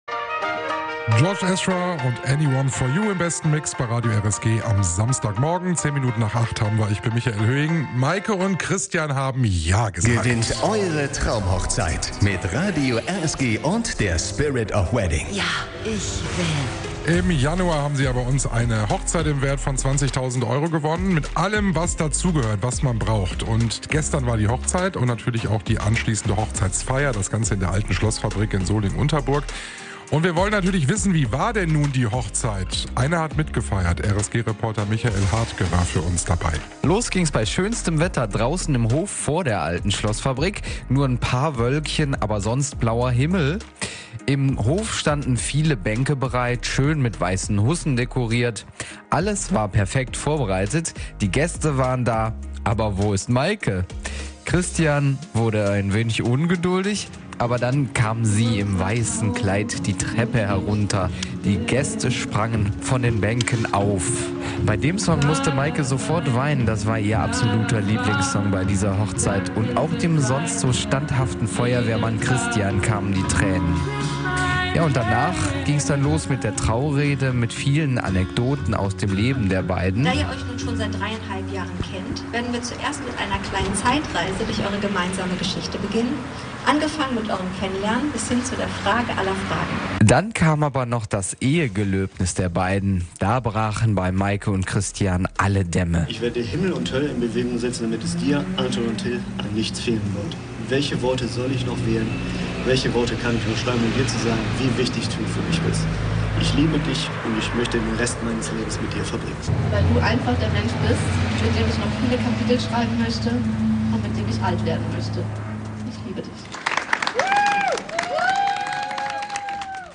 TraumhochzeitDie Trauung